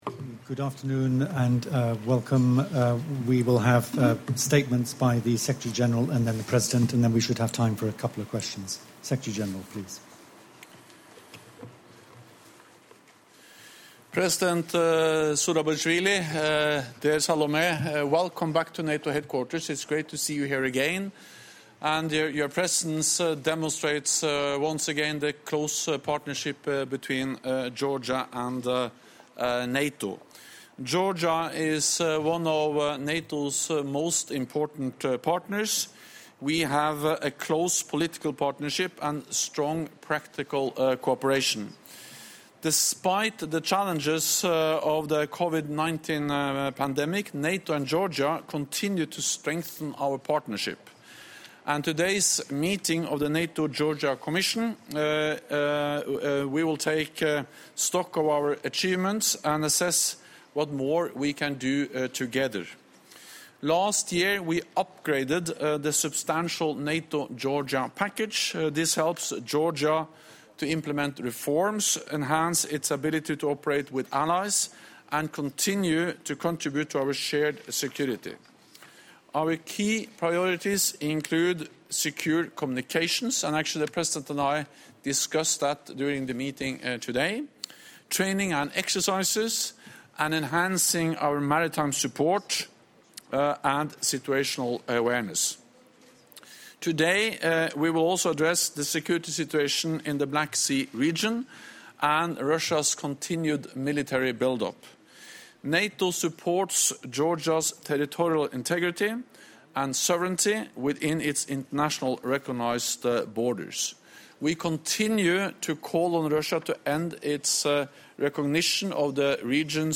Joint press point with NATO Secretary General Jens Stoltenberg and the President of Georgia, Salome Zourabichvili